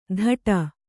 ♪ dhaṭa